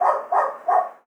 dog_bark_small_08.wav